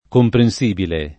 [ kompren S& bile ]